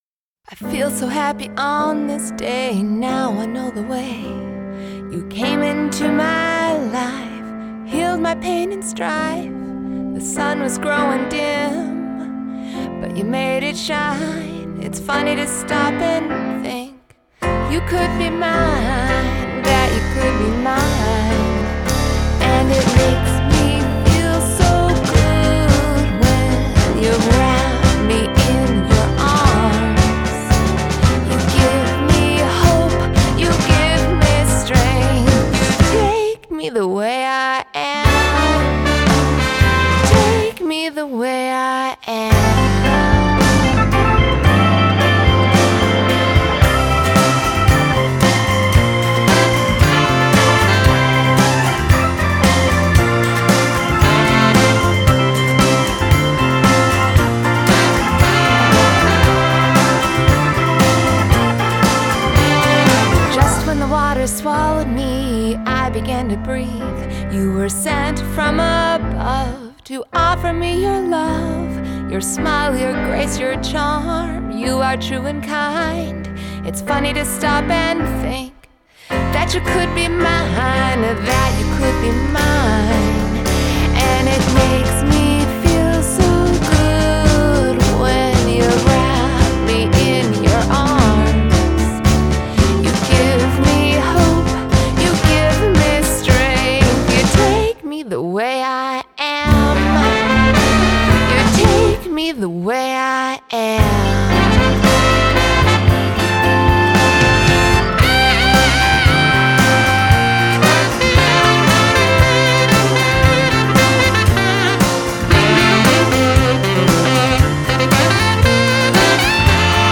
Adult Contemporary
Musical Theatre